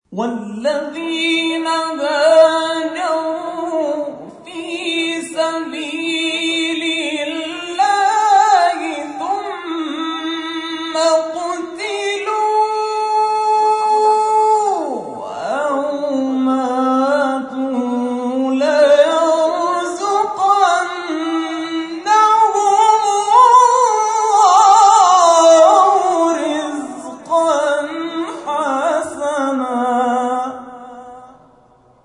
تلاوت قرآن
در ادامه قطعاتی از تلاوت‌ها ارائه می‌شود.
معراج الشهداء